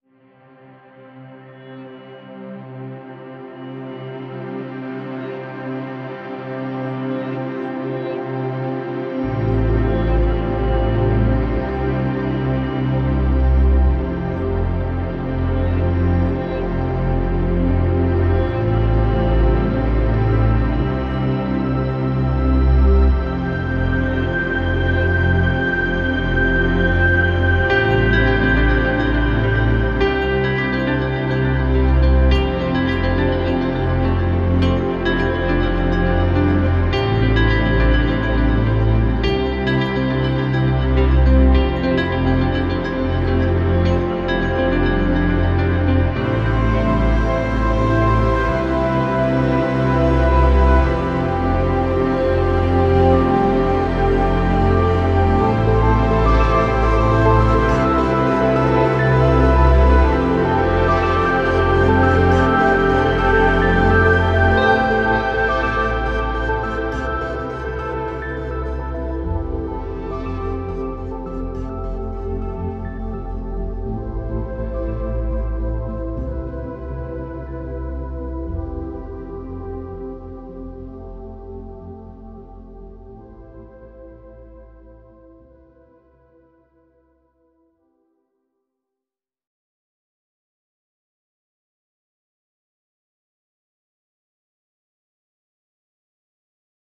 所有声音都是大气的，打击垫和环境运动，适用于音景，环境音乐，预告片音乐和各种寻求丰富而深沉的声音的流派。
Patmos 使用在模拟硬件合成器上制作的原始补丁进行采样。有些补丁是多层的，有些经过大量处理以获得正确的结果。
除了环境和大气氛围外，一些补丁与原声钢琴完美融合。